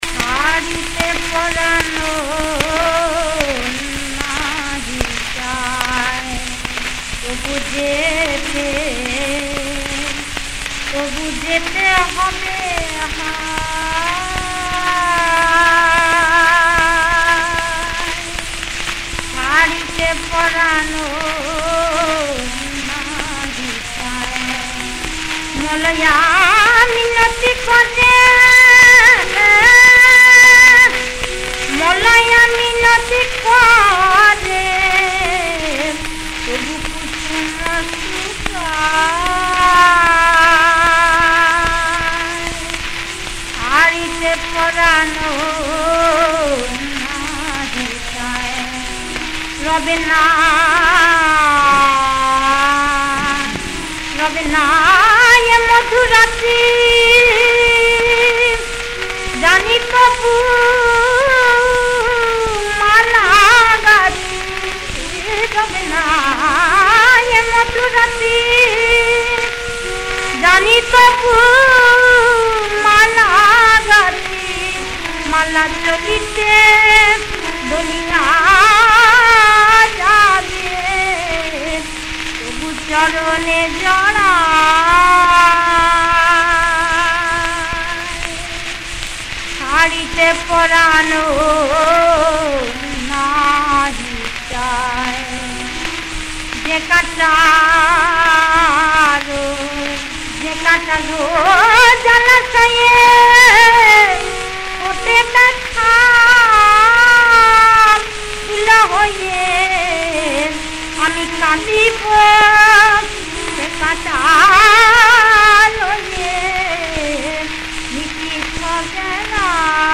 খাম্বাজ-দাদরা]
রাগ: খাম্বাজ।